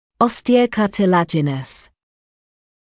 [ˌɔstɪəuˌkɑːtɪ’læʤɪnəs][ˌостиоуˌкаːти’лэджинэс]костно-хрящевой